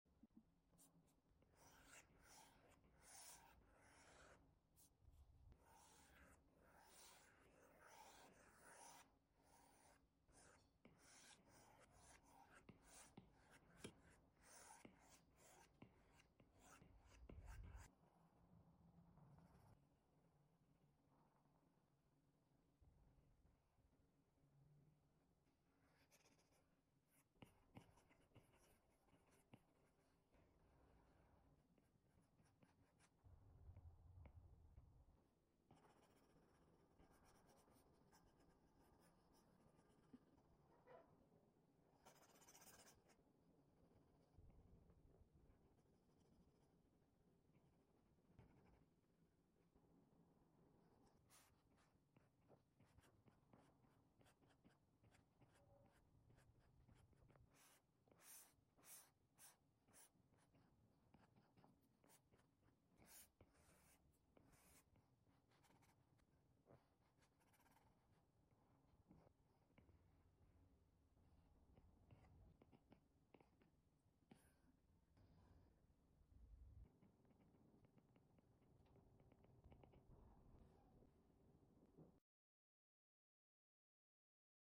The hair brush makes drawing sound effects free download